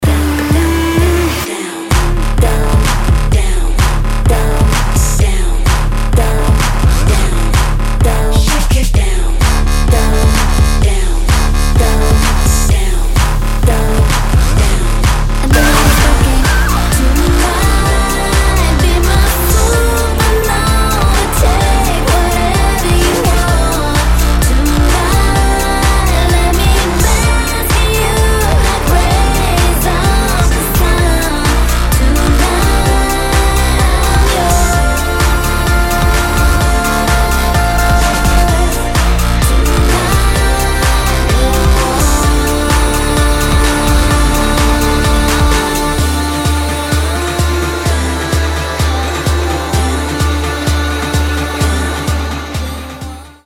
• Качество: 320, Stereo
Electro Pop